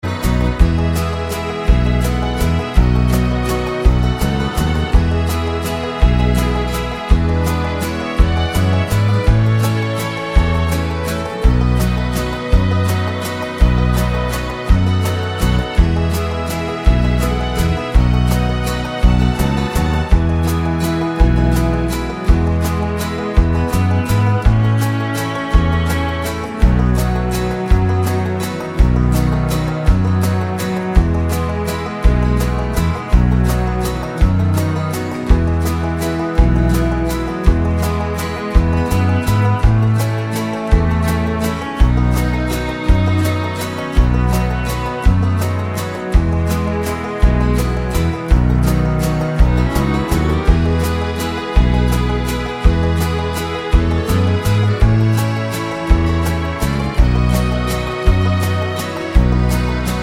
no Backing Vocals Irish 3:55 Buy £1.50